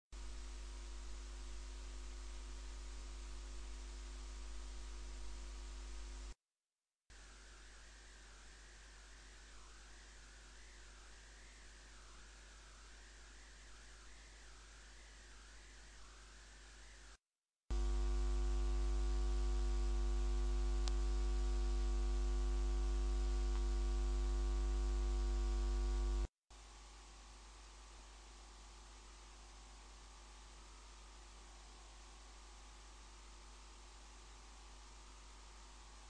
My demands for this mic aren't that high, but it should do it's job (especially the guitar recording) kinda nice without static noise in the background. my setup: I have a MXL 2006 condenser microphone which is connected with a Tube Mp preamp through an XLR cable.
Ive attached a test audio example of my static background noise in different setups. 1.